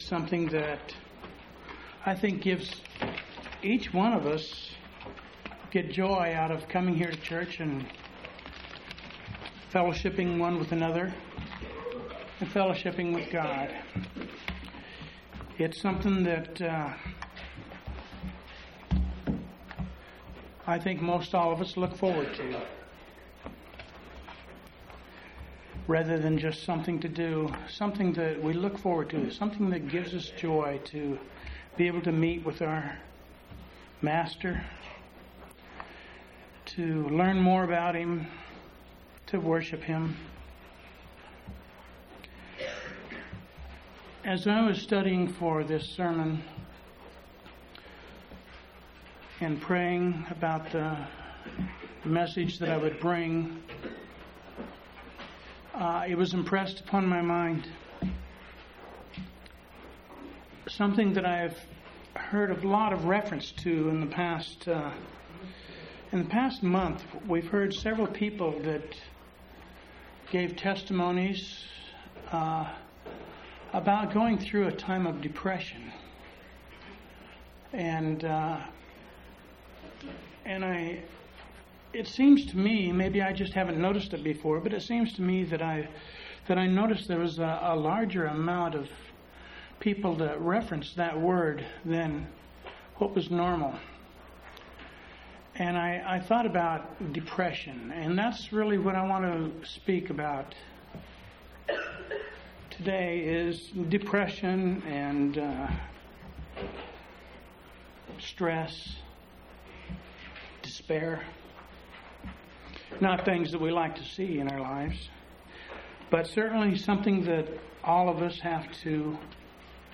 12/20/1998 Location: Phoenix Local Event